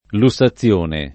[ lu SS a ZZL1 ne ]